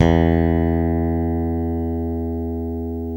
Index of /90_sSampleCDs/Roland L-CD701/BS _Jazz Bass/BS _Jazz Basses